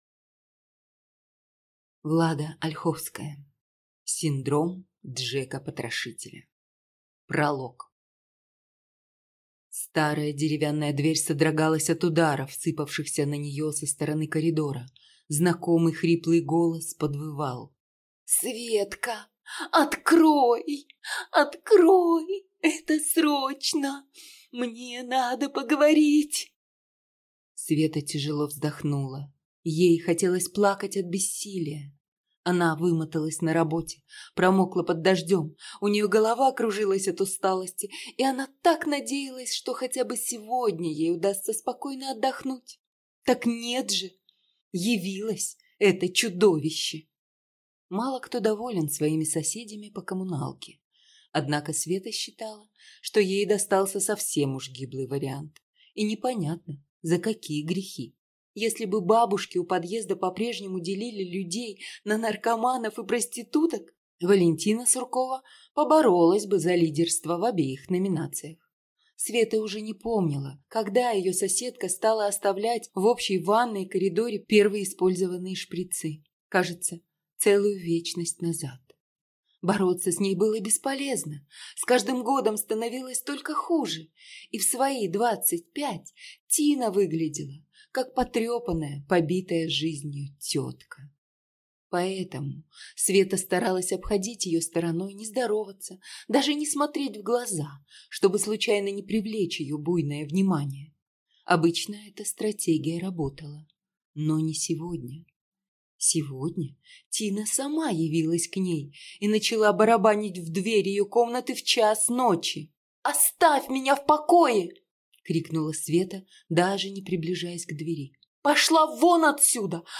Аудиокнига Синдром Джека-потрошителя | Библиотека аудиокниг